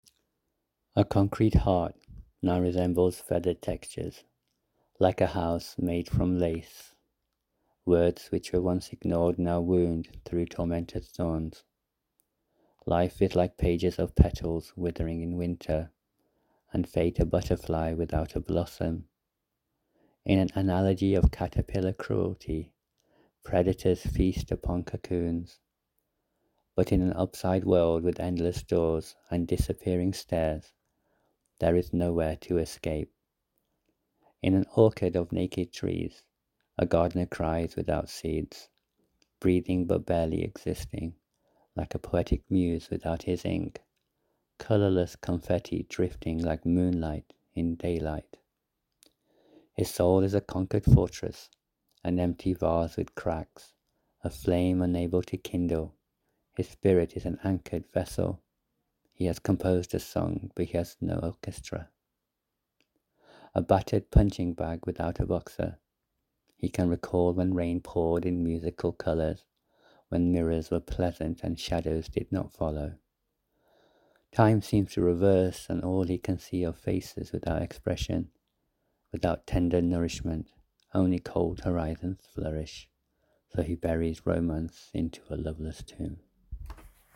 It was nice listening to your recital.
wow, I was hypnotized by your voice, and the cadence of your poetry is simply sublime, :)